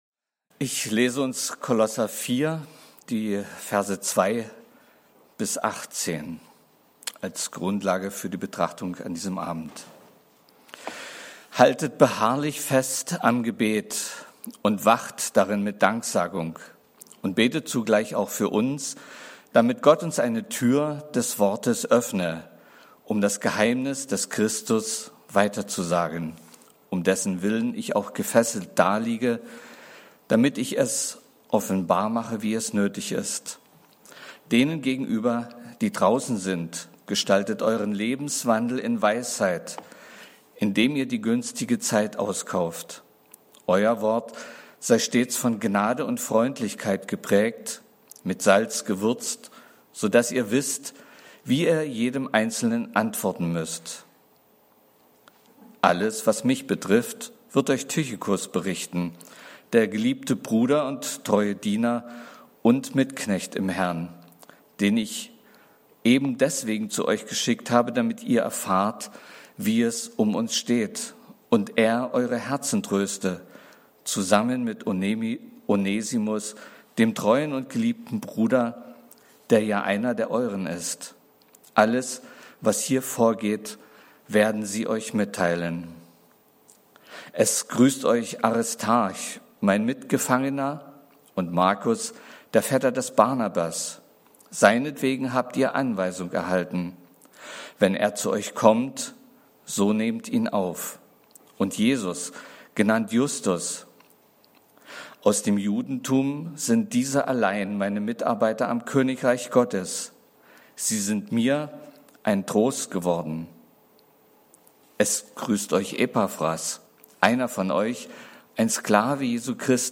Podcasts de Gottesdienste